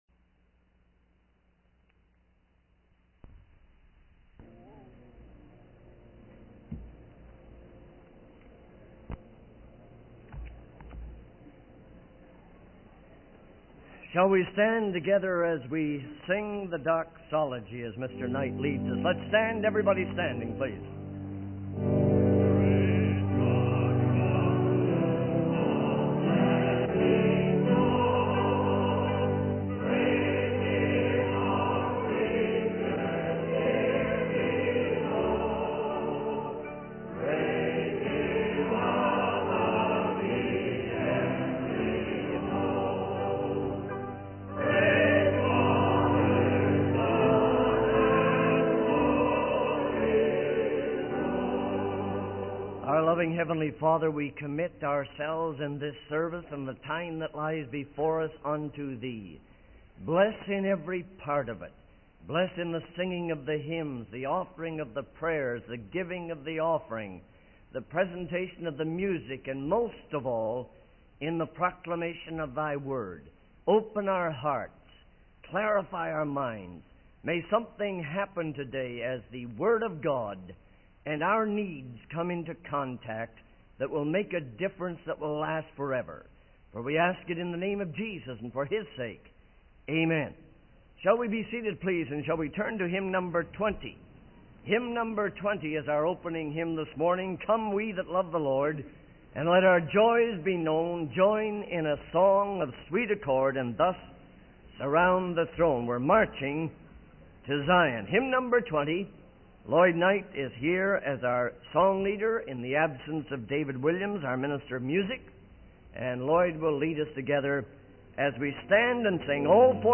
The sermon transcript is a compilation of various hymns and verses from the Bible. The speaker emphasizes the importance of carrying everything to God in prayer and the peace that comes from doing so. The sermon also highlights the concept of confessing sins and seeking forgiveness from God, emphasizing that there is hope and provision for those who fail.